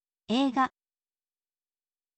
eiga, เออิกะ, เอกะ